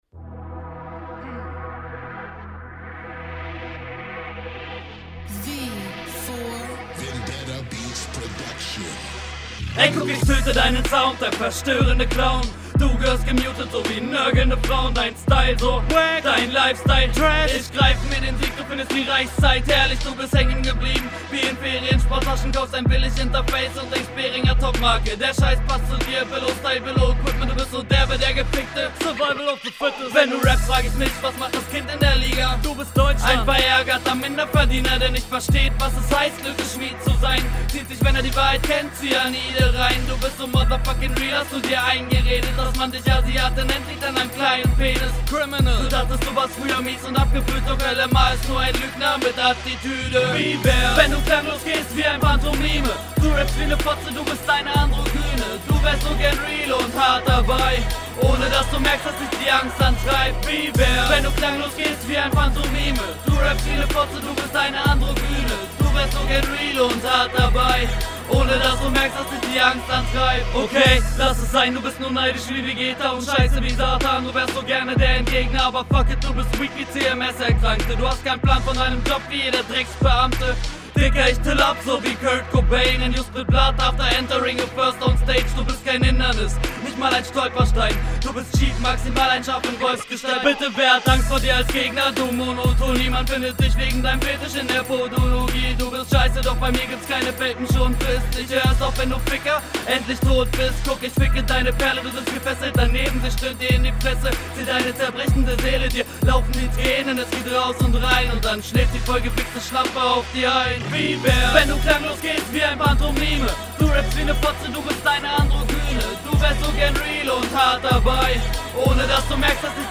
Stimme muss aufjedenfall lauter.
Stimme geht ein klein wenig unter, ist noch verständlich, aber musste mich konzentrieren.
Beat gefällt mir, schön dirty Abmische genau wie in deiner RR.